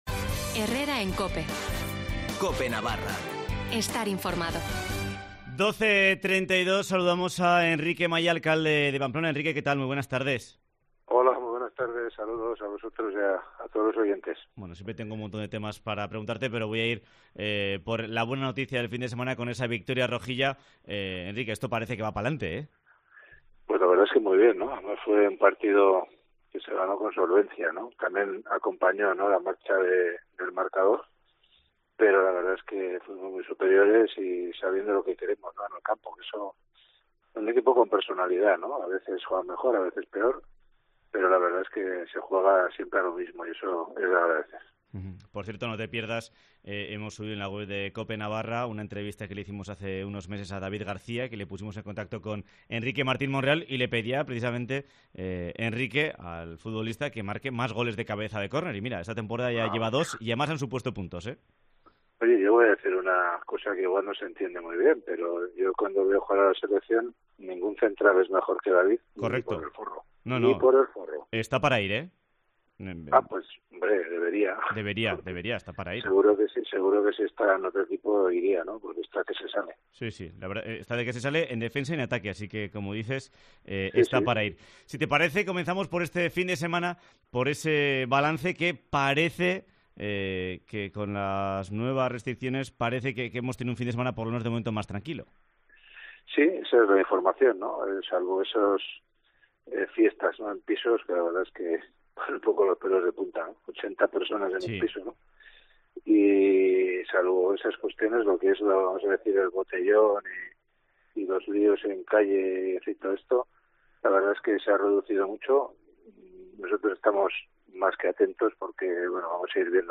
Entrevista con Enrique Maya, alcalde de Pamplona